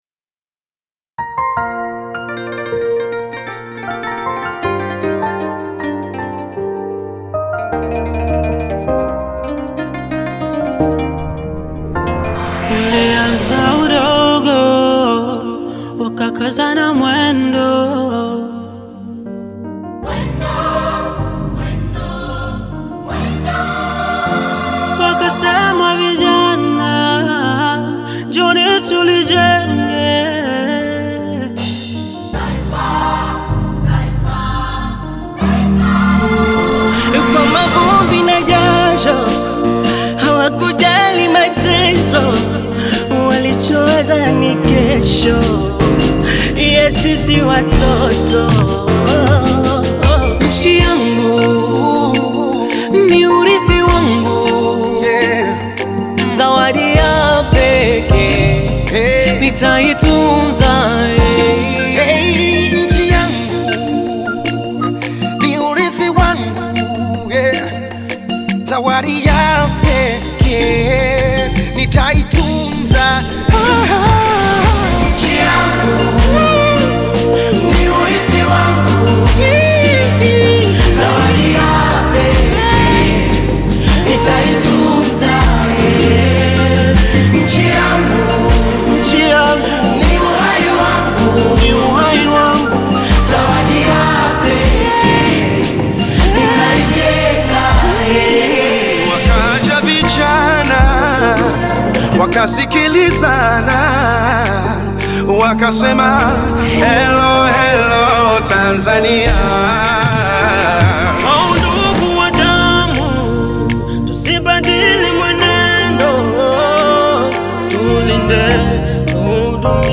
-CHORUS
> Saxophone